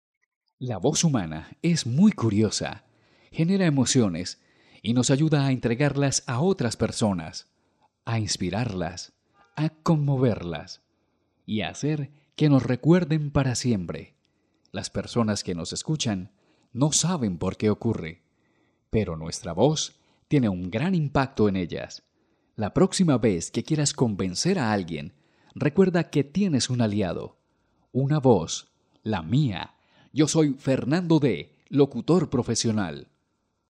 Sprechprobe: Werbung (Muttersprache):
Professional voice for recording Videos for television, youtube and other social networks Professional voice for educational and explanatory content elearning Professional voice for radio and television commercials Professional Voice for PBX - IVR Telephone or Audio response systems Professional audio recording and editing